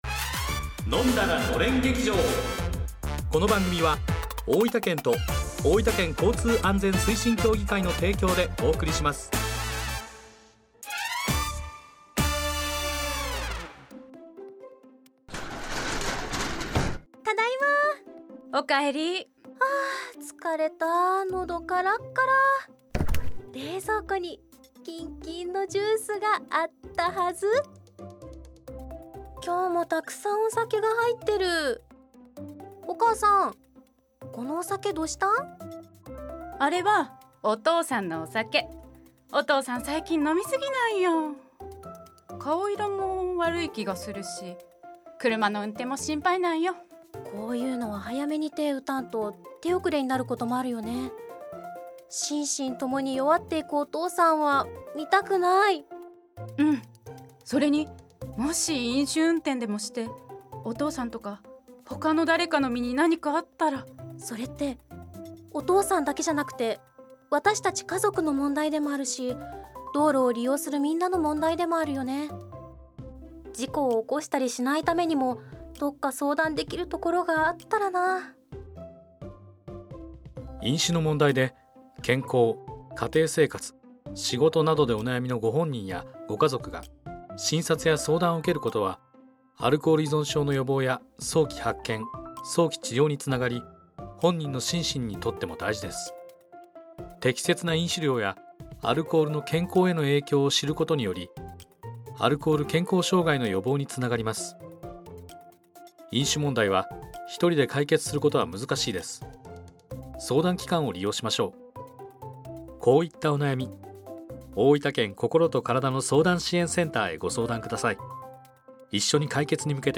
ラジオドラマ「飲んだらのれん劇場」をFM大分にて放送しました。